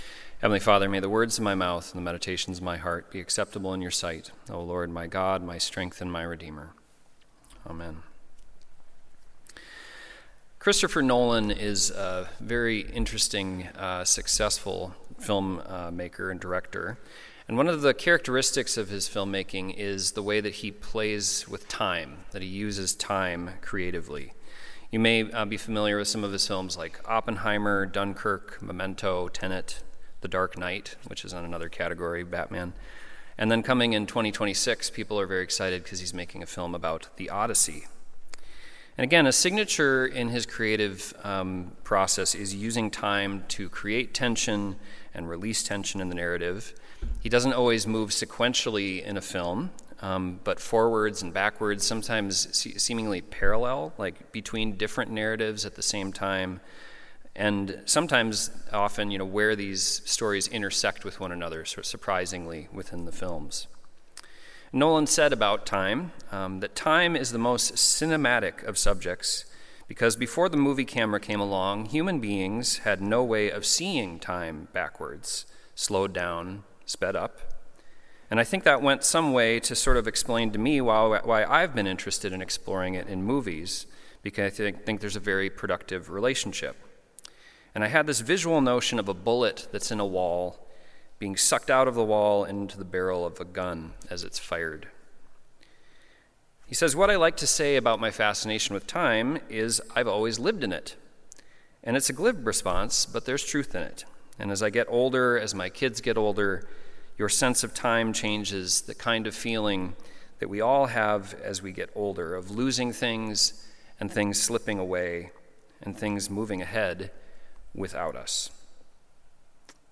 Sunday Worship–May 4, 2025
Sermons